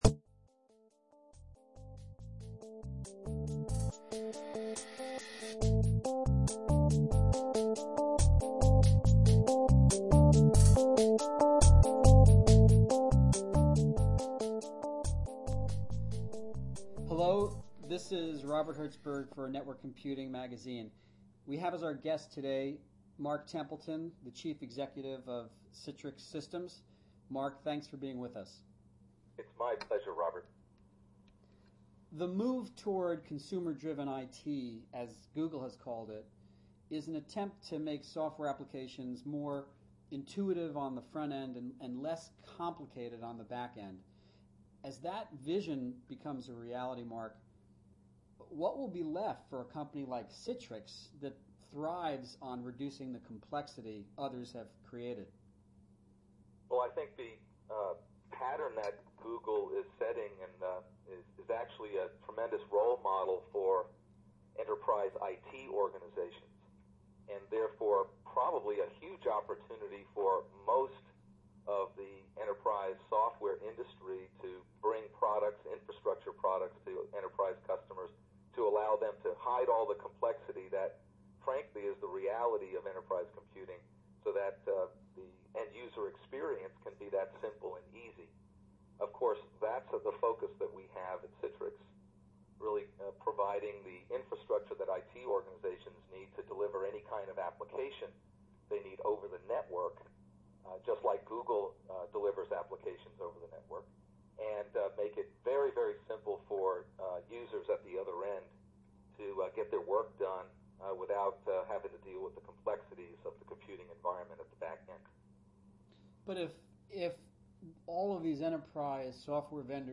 The NWC Interview